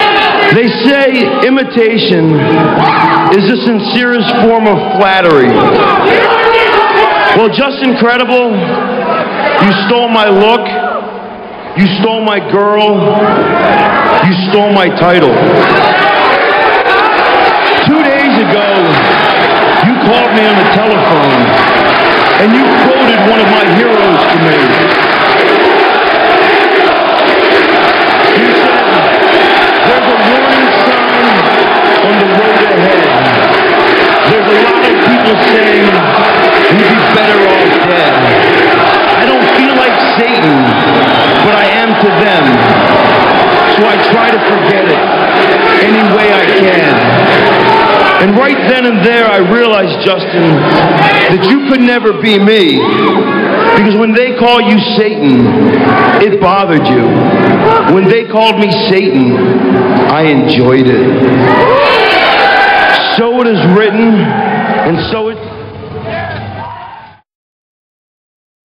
- This clip comes from ECW on TNN - [6.02.00]. Raven cuts a promo stating that being Satan doesn't bother Raven like it does Justin, because he enjoys it, like the Neil Young song says. - (1:08)